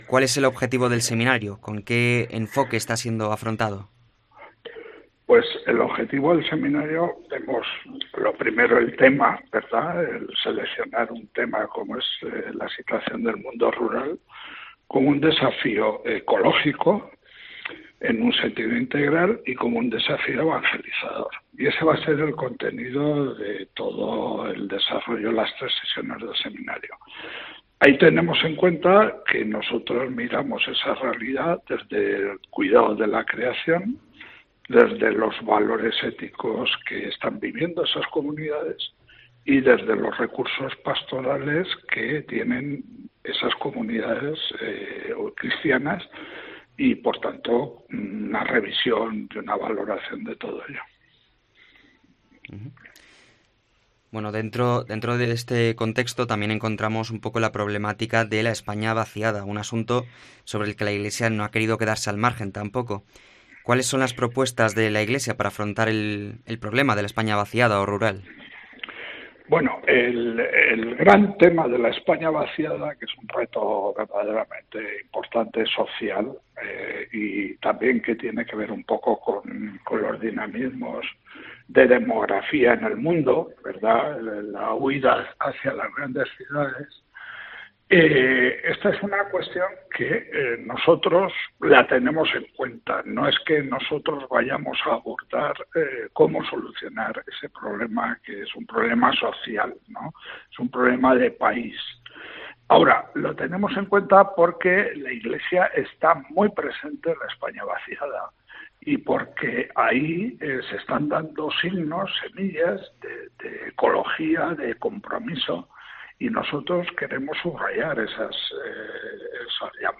entrevistar